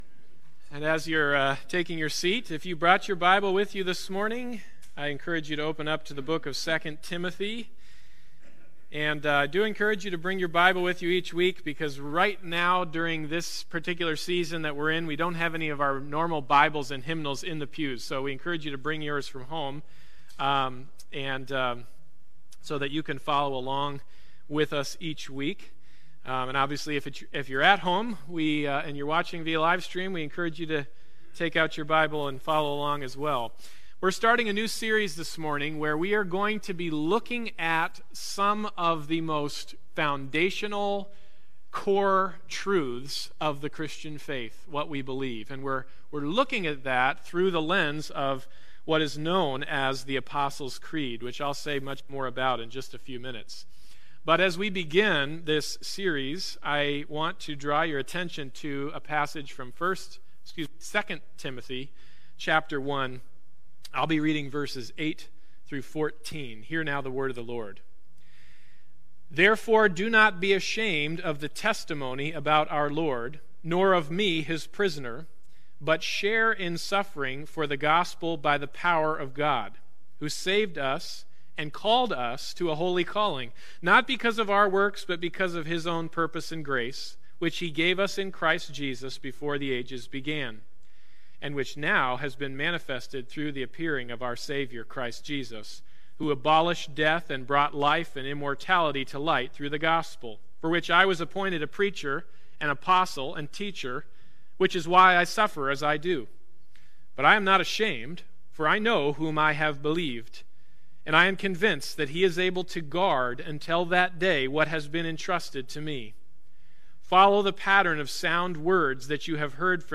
2 Timothy 1:8-14 Service Type: Sunday Morning Service « How Long Till the End?